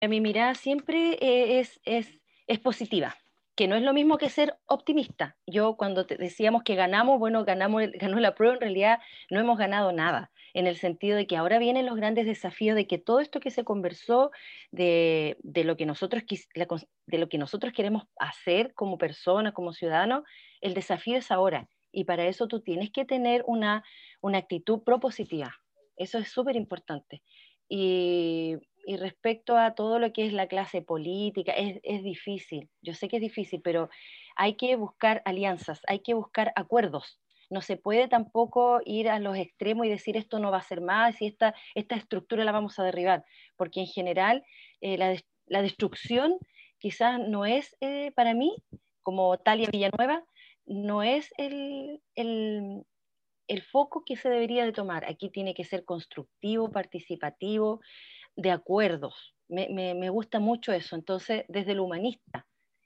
En conversación con Radio UdeC, la precandidata manifestó sentirse profundamente identificada con el proyecto de Municipio Ciudadano, ya que incluye ejes y valores importantes que comparte, como la democracia e integración de los barrios o el cuidado del medio ambiente.